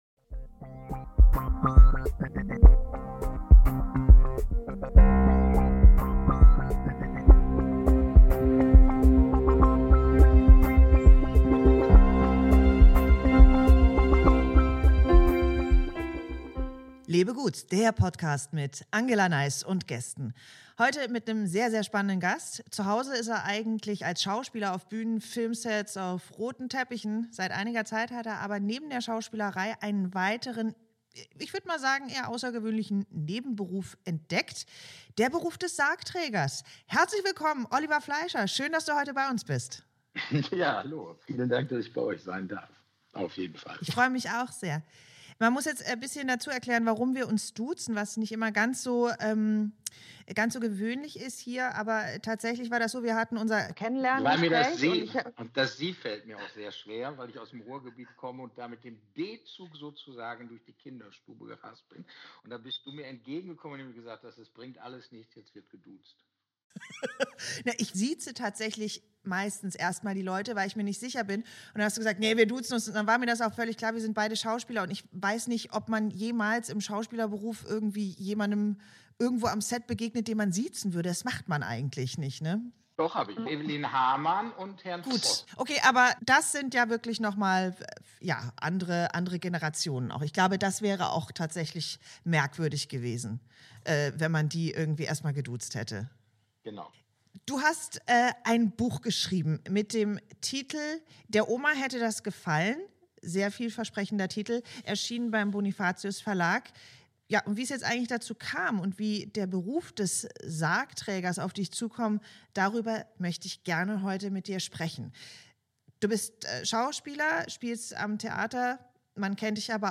Ein Gespräch über das Sterben, das Mut macht, zu leben – und über das Leben, das auch im Tod noch für eine Pointe gut ist. Und das alles zwischen zwei Schauspielkollegen, die beweisen: Auch der letzte Vorhang kann mit Applaus fallen.